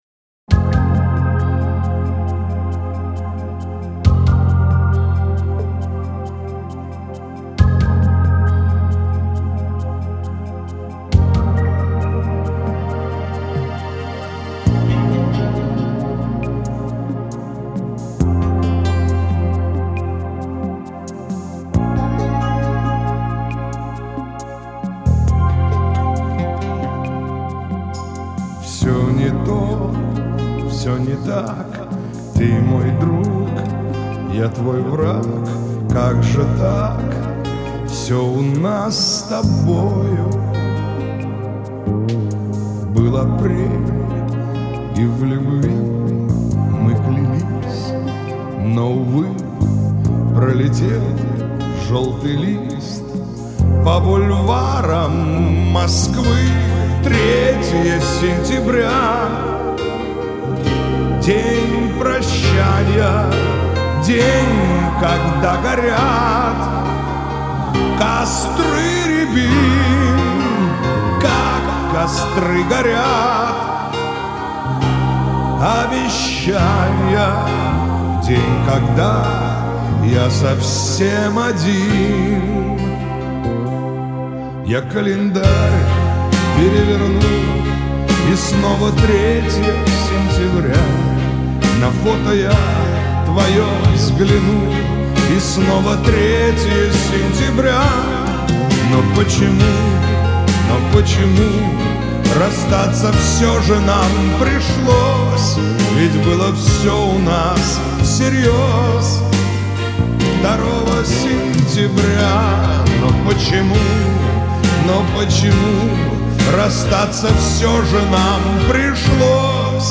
Качество: 192 kbps, stereo